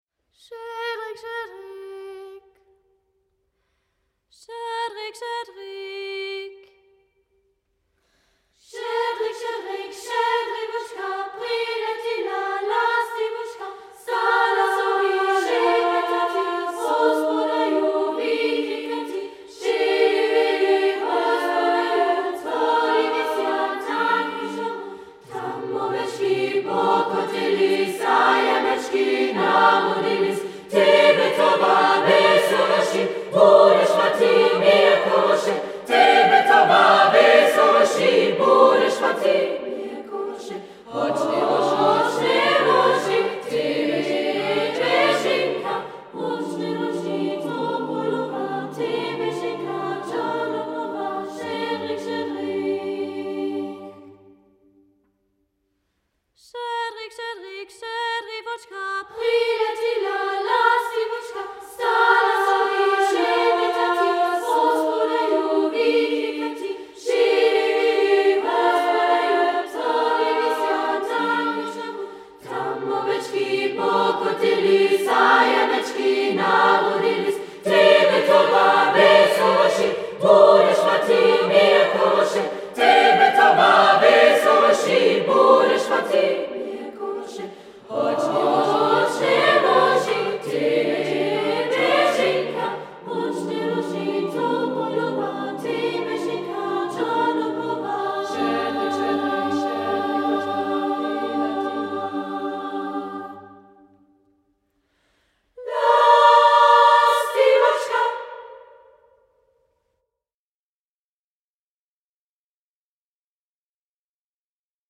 Chor im Monat Jänner 2022